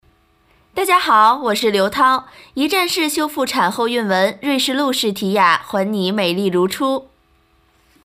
国语女声 张雨绮（明星模仿）